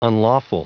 Prononciation du mot unlawful en anglais (fichier audio)
Prononciation du mot : unlawful